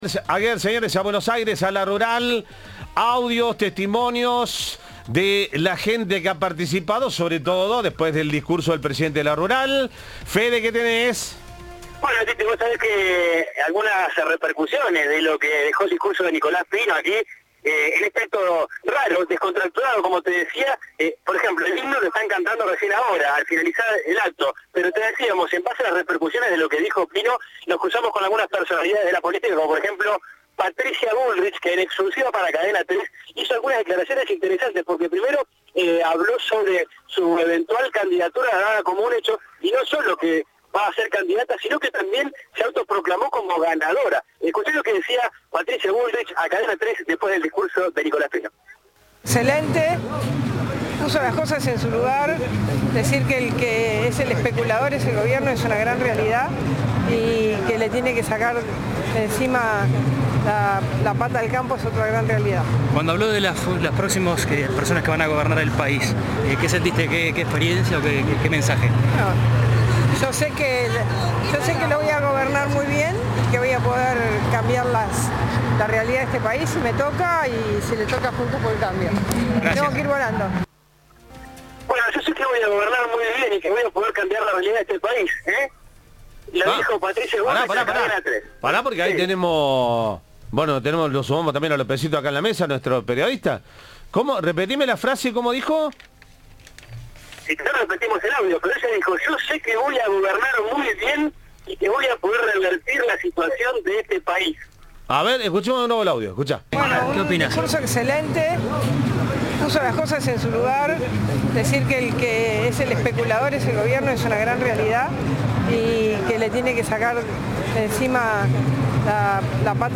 La presidenta del PRO estuvo en la inauguración de La Rural en Palermo y dejó entrever la idea de lanzar su candidatura presidencial en las elecciones del próximo año.
"El discurso de Pino fue excelente y puso las cosas en su lugar al decir que el especulador es el Estado y no el campo", comenzó diciendo en un breve diálogo que la presidenta del PRO mantuvo con Cadena 3.